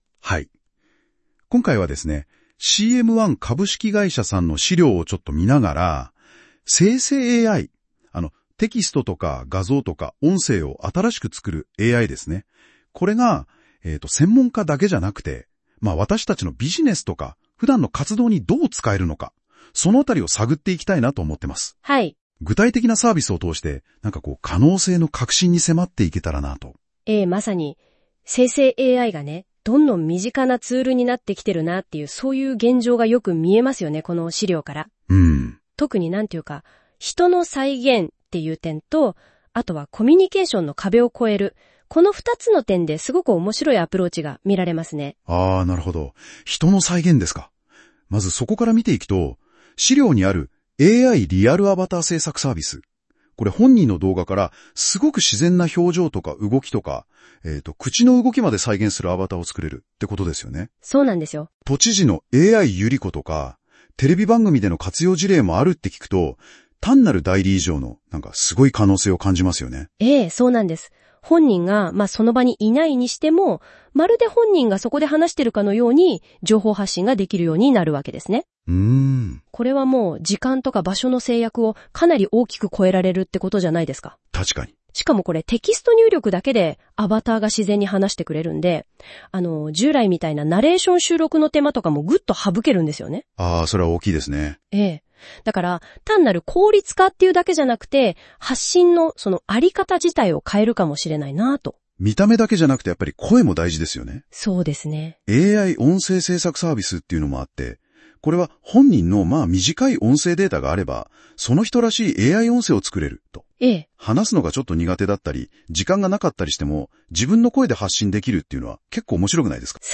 当社の 5 つの生成 AI サービスが “どんな課題をどう解決するか” を、ミニ番組形式でまとめました。
※本画像と音声ガイダンスは、生成 AI で制作しています。